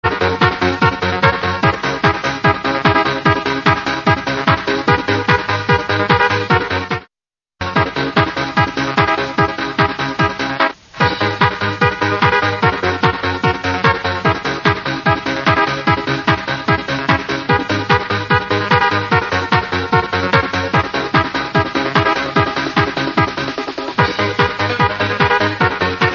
Old Trance from 1996, pls i need ID it!!!!!!!!!!!!!!!!!!!!!
it's a bit like "prayer 5 - we can fly", same chords and style not very different.